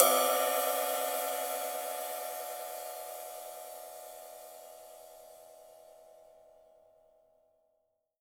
Index of /90_sSampleCDs/Best Service ProSamples vol.43 - Real Drum Kits [AIFF, EXS24, HALion, WAV] 1CD/PS-43 WAV REAL DRUMS/SWING KIT